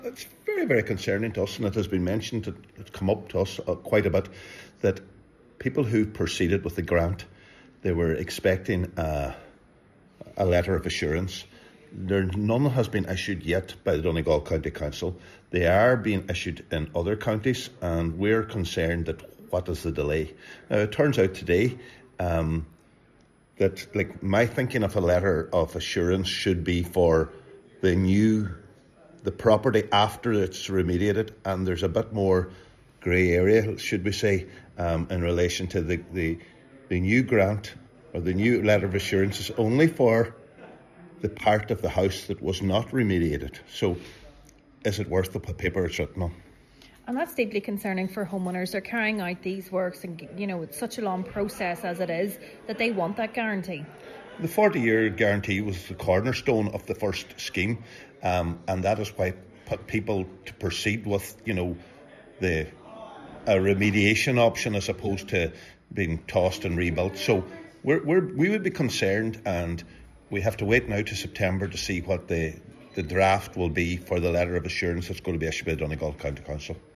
This, Councillor Ali Farren says is extremely worrying: